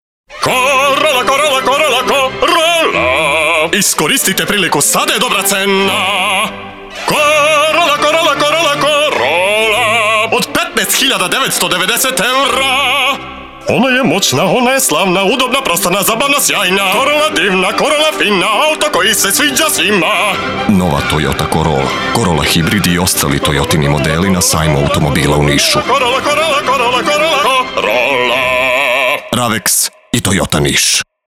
U kategoriji „Reklame do 40 sekundi“ osvojili smo zlatnog vRABca za reklamu „Toyota Corolla„:
Toyota-Corolla-30s.mp3